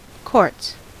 Ääntäminen
Ääntäminen US : IPA : [kɔː(r)ts] Haettu sana löytyi näillä lähdekielillä: englanti Courts on sanan court monikko.